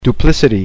[doo- plis -i-tee, dyoo-]